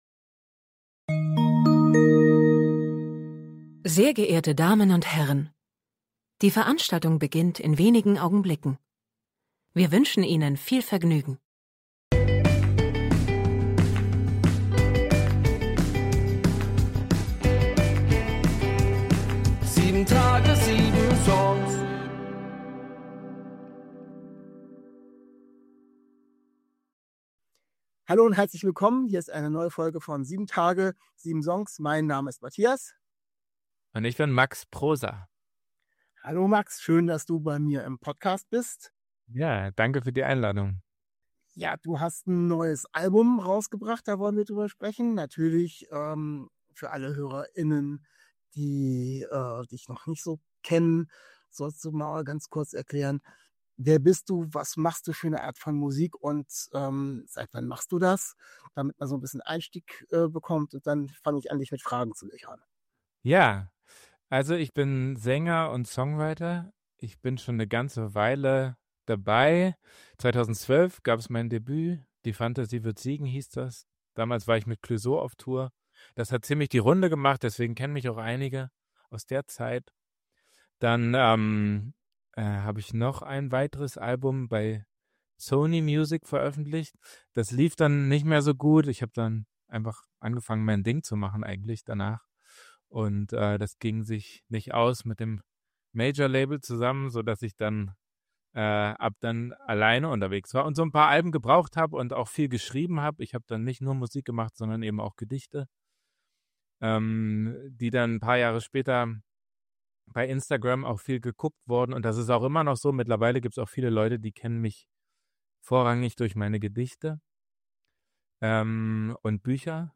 Letzte Episode #6.7 Zu Gast: Max Prosa 14. Februar 2026 Nächste Episode download Beschreibung Kapitel Teilen Abonnieren Zu Gast ist der Singer- Songwriter Max Prosa. Wir sprechen über sein neues Album "Der Garten".